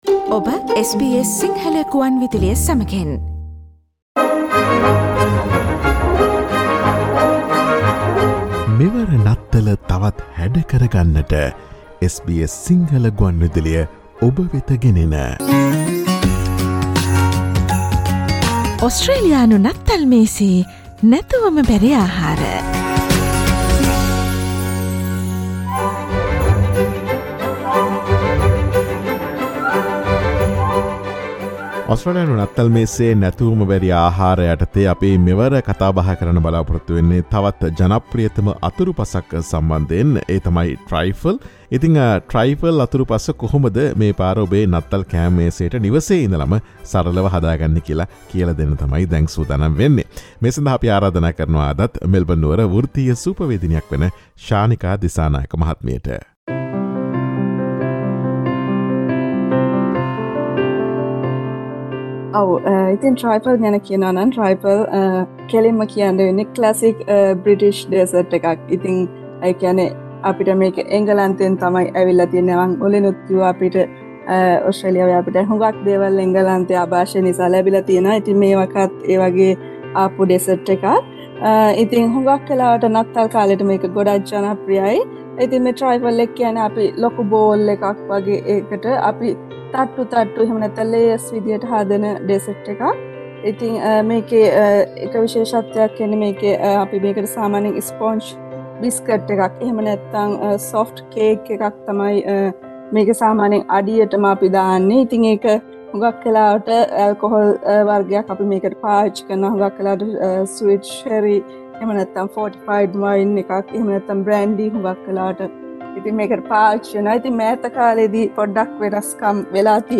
ඔස්ට්‍රේලියානු නත්තල් මේසයේ නැතුවම බැරි ආහාර යටතේ ඔස්ට්‍රේලියාවේ ජනප්‍රියතම අතුරුපසක් වන Trifle මෙවර නත්තල සඳහා සාදාගන්නා අයුරු පැහැදිලි කරන SBS සිංහල ගුවන් විදුලියේ නත්තල් විශේෂාංගයට සවන් දෙන්න.